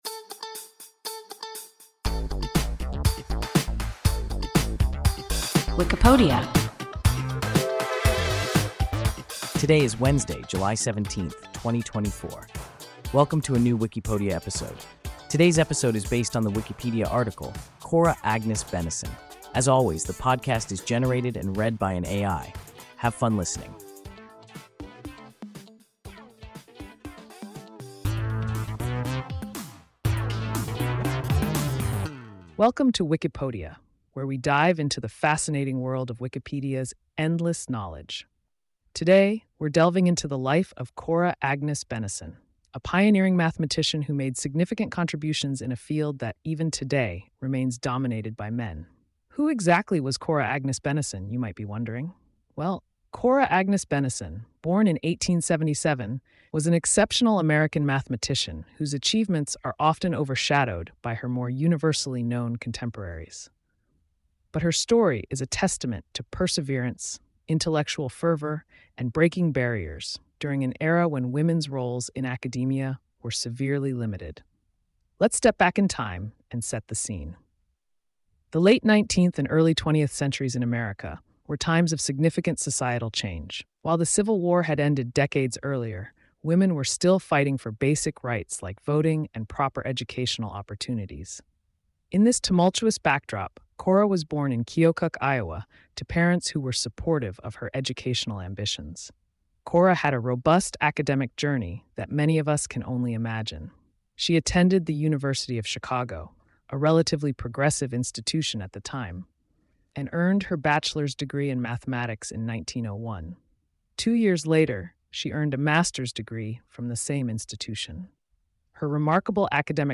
Cora Agnes Benneson – WIKIPODIA – ein KI Podcast